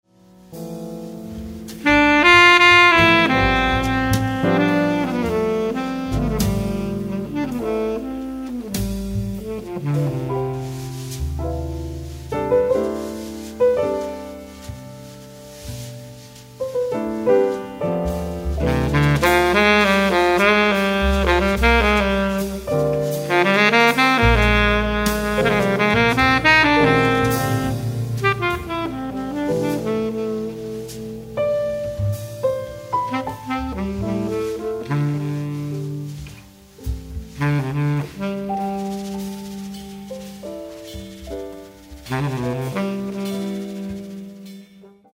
All I said was let's play around with an 'F' tonality.